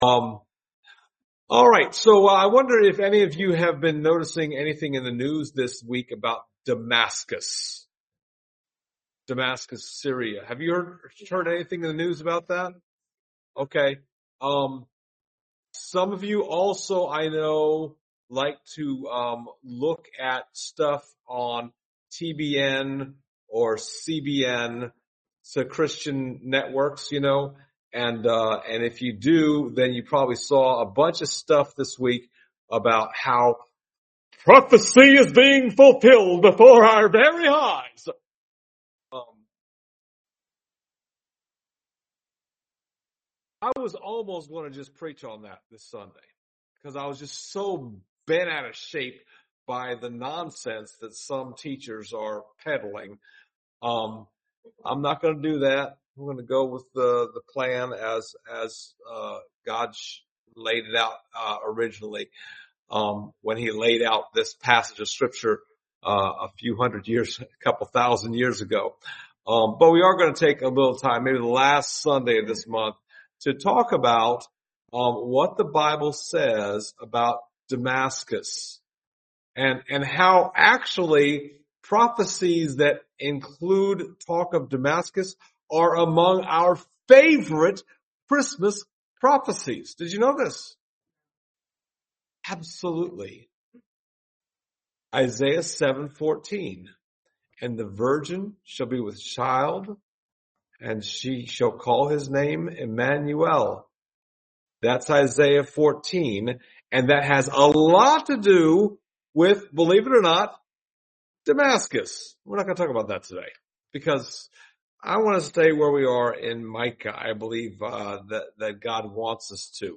Passage: Micah 5:1-5 Service Type: Sunday Morning